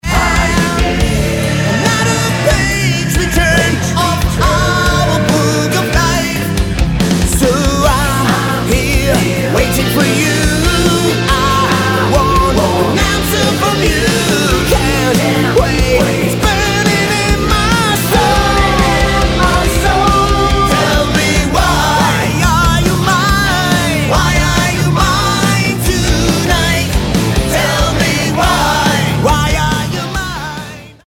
Lead Vocals
Bass, Vocals
Gitarre, Vocals
Drums, Vocals